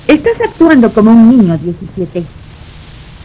Por motivos de espacion estos sonidos estan hechos en formato wav de 8 bits, por eso es que no tienen mucha calidad, si quieres oir las versiones mas claras, solo Escribeme Y yo te mando los MP3 sin ningun problemas.